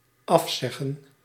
Ääntäminen
IPA: /ˈɑfˌsɛɣə(n)/